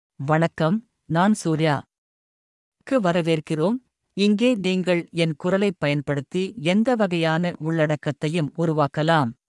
Surya — Male Tamil (Malaysia) AI Voice | TTS, Voice Cloning & Video | Verbatik AI
Surya is a male AI voice for Tamil (Malaysia).
Voice sample
Listen to Surya's male Tamil voice.
Male
Surya delivers clear pronunciation with authentic Malaysia Tamil intonation, making your content sound professionally produced.